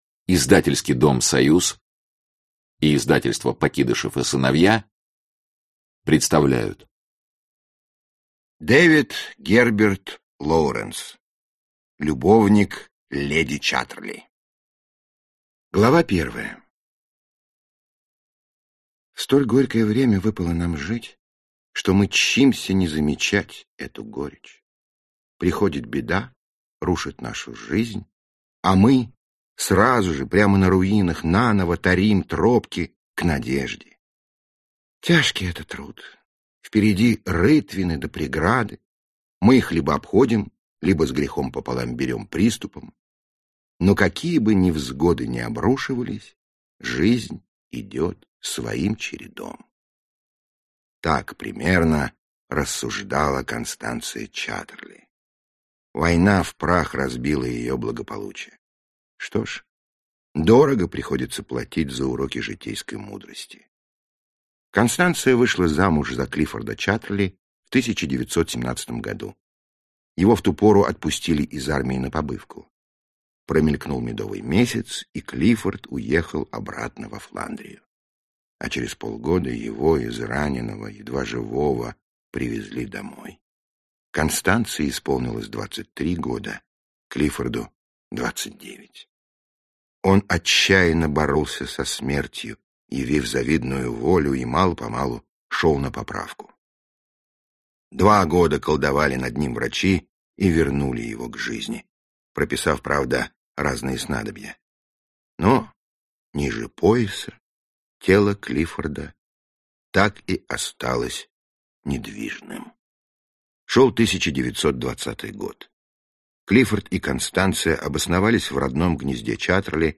Аудиокнига Любовник леди Чаттерли | Библиотека аудиокниг
Aудиокнига Любовник леди Чаттерли Автор Дэвид Герберт Лоуренс Читает аудиокнигу Михаил Горевой.